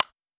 Bat Hit Wood Short.wav